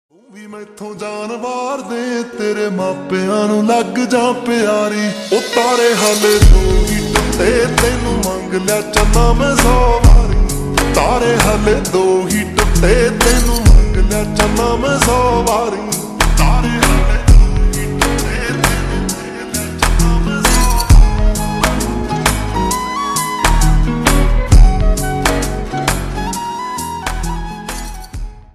Punjabi sang
(Slowed + Reverb)